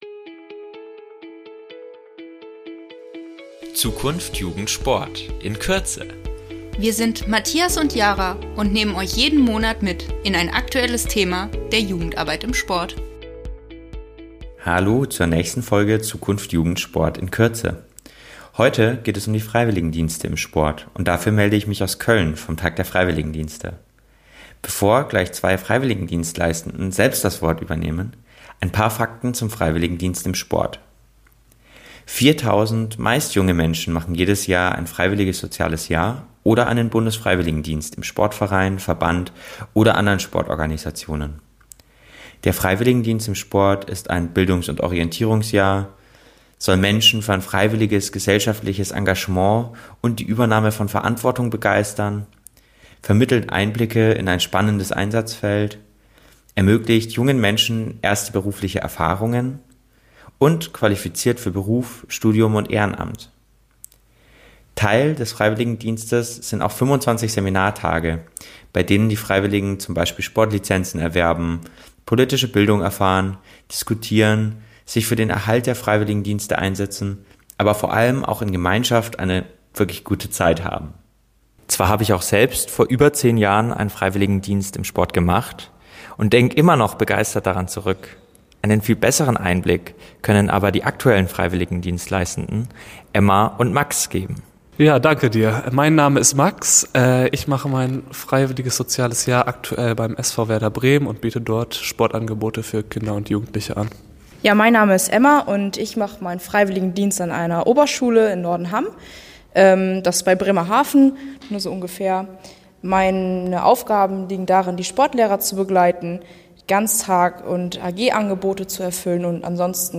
Köln vom Tag der Freiwilligendienste.